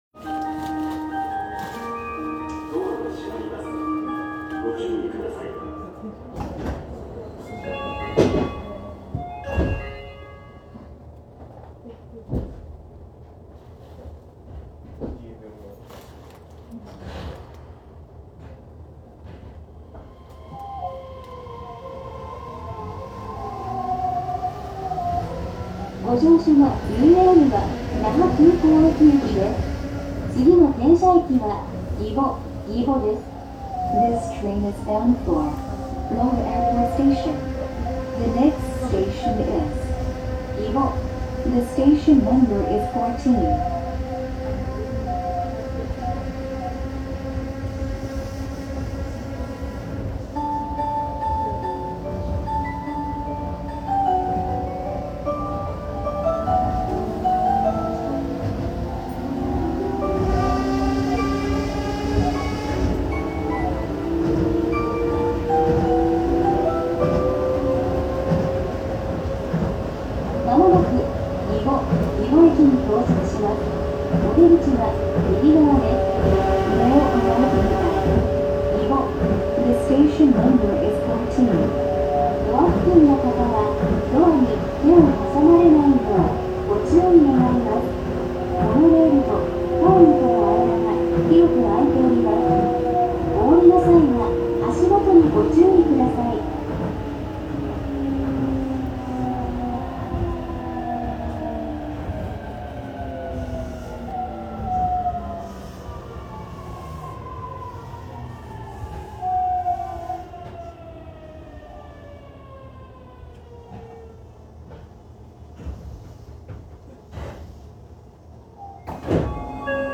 各駅到着前には沖縄民謡をアレンジした車内チャイムが流れるなど、雰囲気作りもしっかりしています。
・1000形後期車・3連走行音
【ゆいレール】首里→儀保…収録は1131Fにて
前期車と後期車＆3連でモーターは異なっており、後者は日立の後期IGBT-VVVFとなっています。
各駅到着前に地域の民謡が流れるのが、ゆいレールの「味」といったところ。